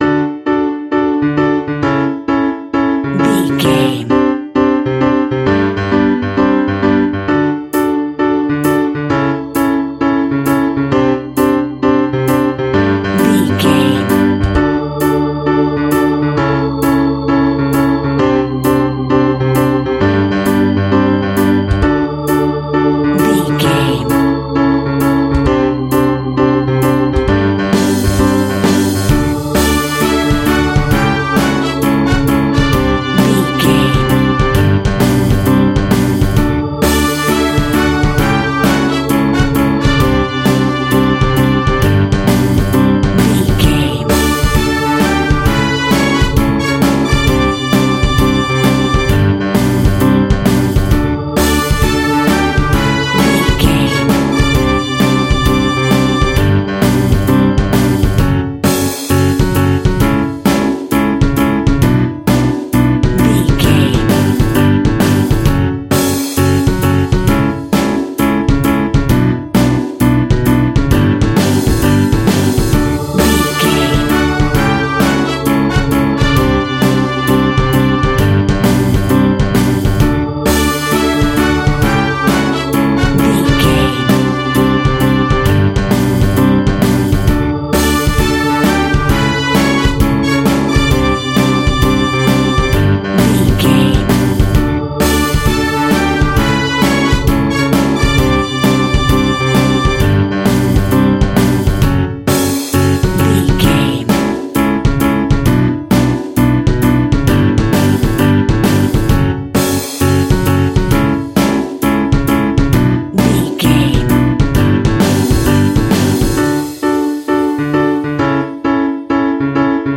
Piano Power Pop.
Ionian/Major
D
pop rock
energetic
uplifting
drums
bass guitar
hammond organ
synth